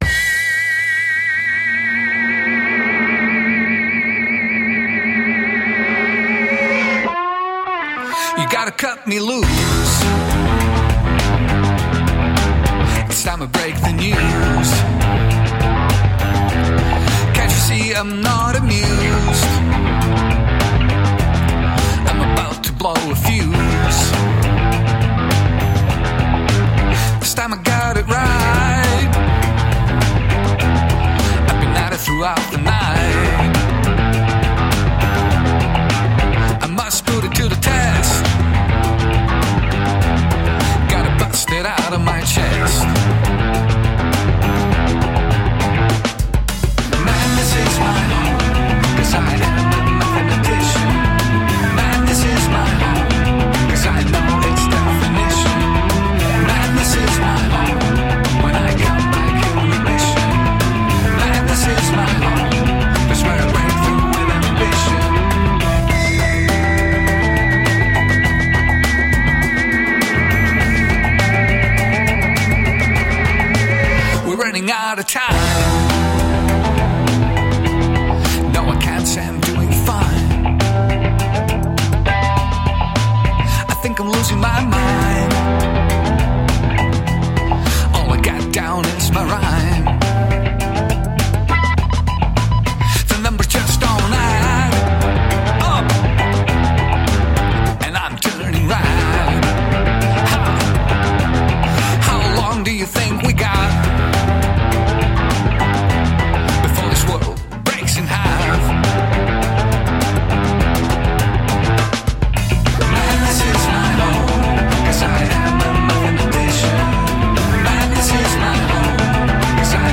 Montreal based duo
Tagged as: Electro Rock, Pop, Alt Rock